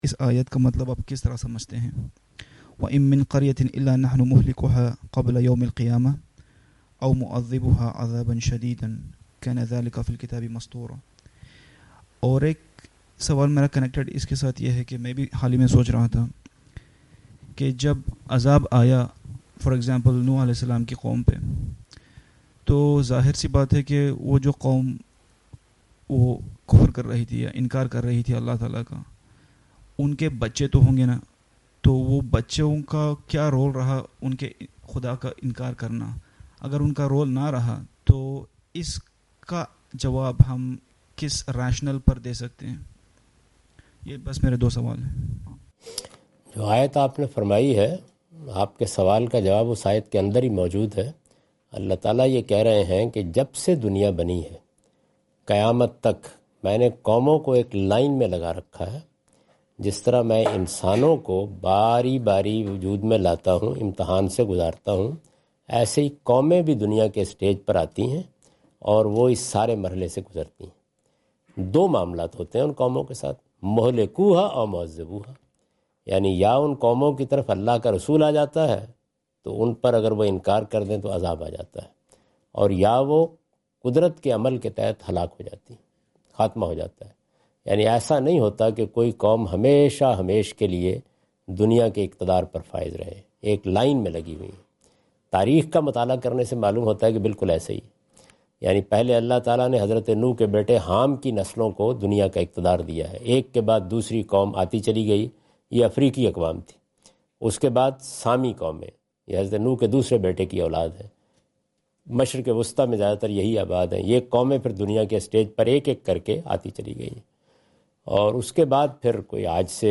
Category: English Subtitled / Questions_Answers /